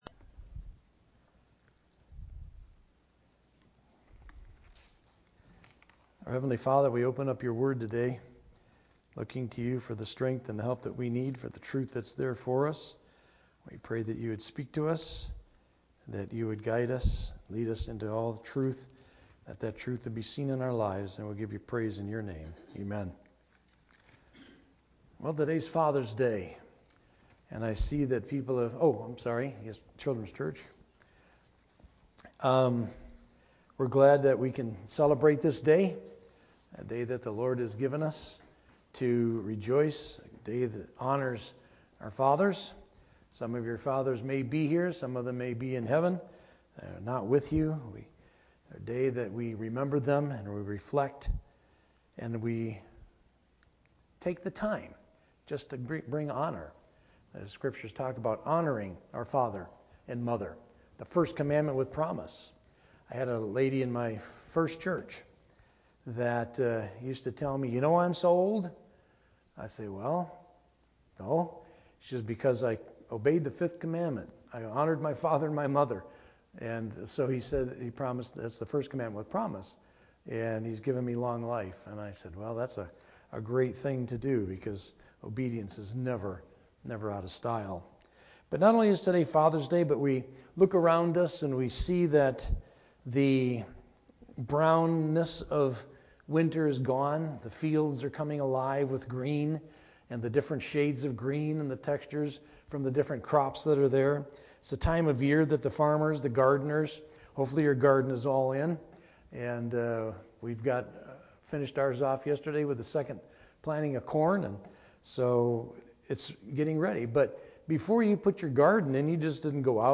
Sermon Audio | FCCNB